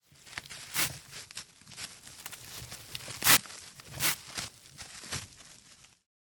bandage_self.ogg